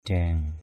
/d̪iaŋ/ (đg.) giăng = tendre. diang talei d`/ tl] giăng dây = tendre une corde; diang asaih d`/ a=sH dòng ngựa = mettre un cheval au piquet.
diang.mp3